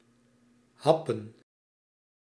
Ääntäminen
IPA: [mɔʁdʁ]